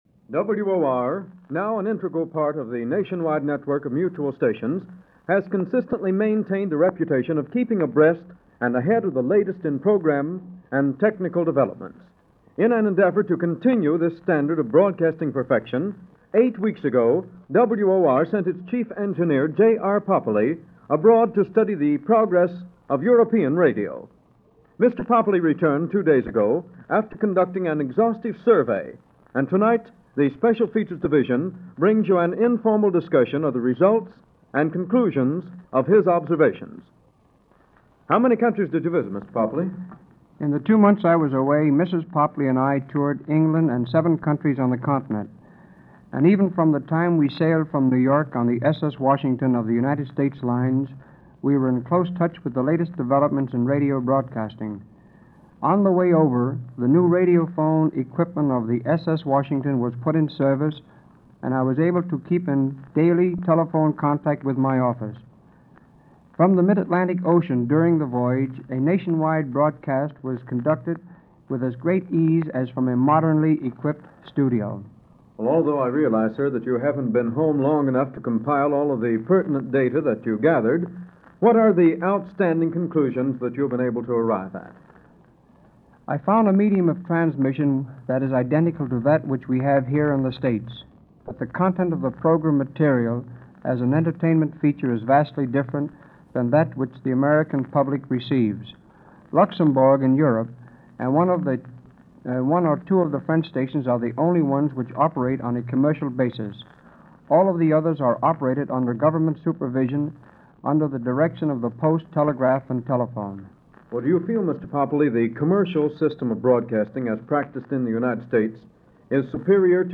Report On Television - May 28, 1938 - Past Daily After Hours Reference Room - report from WOR/Mutual on state of TV Technology
This program, a discussion on, not only Television, but on several other technological advances over the year of 1938, took place at WOR in New York with an engineer, returning from Europe on a fact-finding mission as to just what the state of TV and Facsimile (Fax) and regular radio broadcasting throughout the rest of the world.